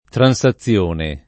transazione [ tran S a ZZL1 ne ]